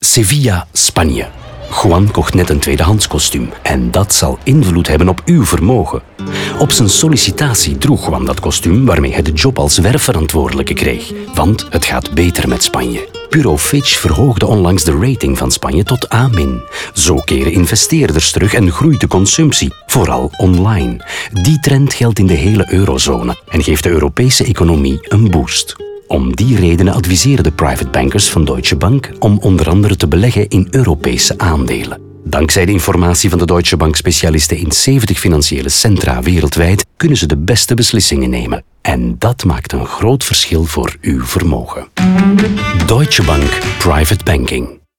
In de radiospots krijgen de verhalen van Rinku, Søren, Juan en Edward ruim de tijd, 45 seconden, om helemaal verteld te worden.